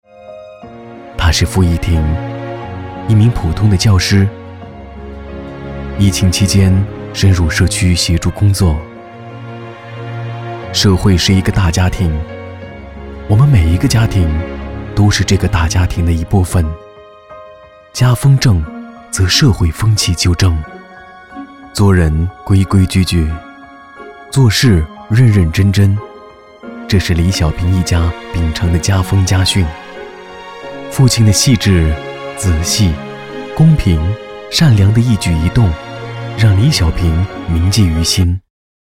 B男176号 | 声腾文化传媒
【专题】深情旁白 让爱回家.mp3